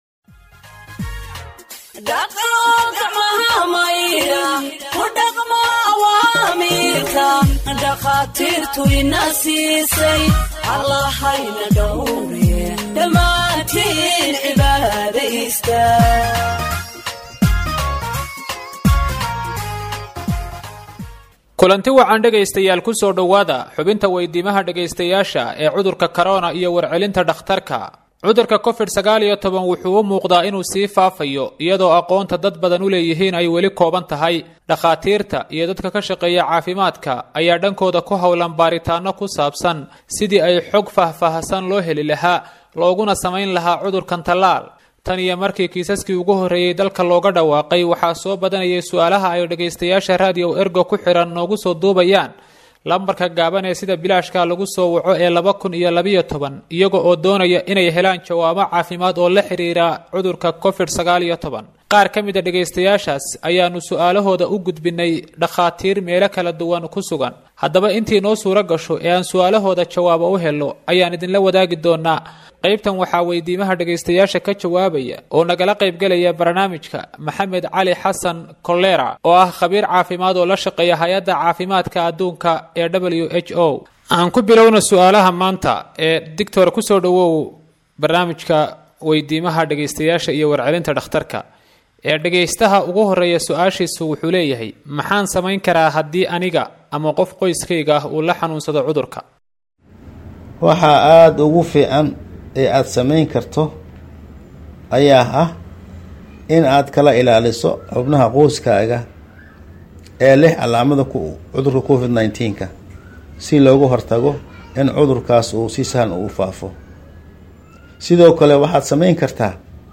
Health expert answers listeners’ questions on COVID 19 (15)